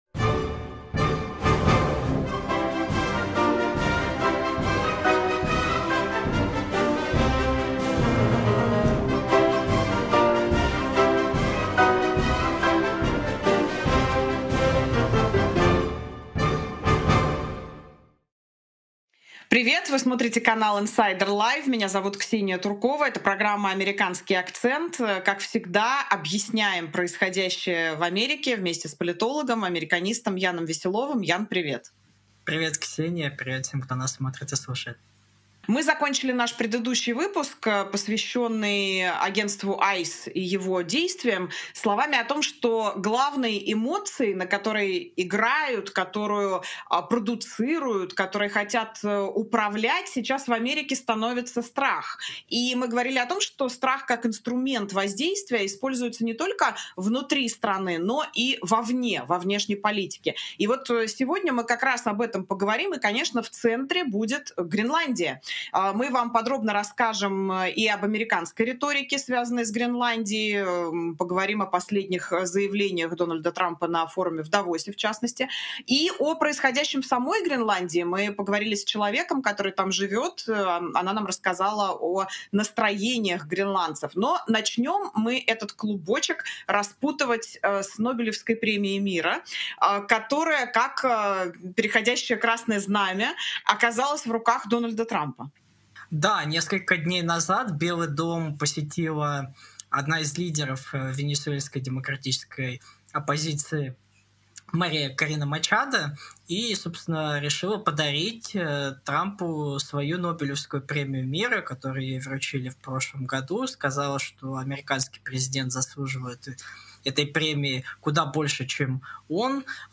Дональд Трамп неожиданно получил Нобелевскую медаль — но есть нюанс. Параллельно Белый дом снова смотрит на Гренландию как на стратегический приз — и мы разбираем, где тут реальная политика, а где шоу для аудитории, и говорим с жительницей Гренландии о настроениях в обществе. В финале обсуждаем Давос: «Совет мира», новые инициативы, старые амбиции и вопрос: зачем это все вообще нужно?